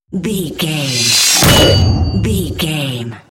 Dramatic horror metal hit 870
Sound Effects
Atonal
heavy
intense
dark
aggressive